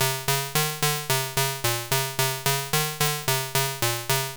70s Random 110-C.wav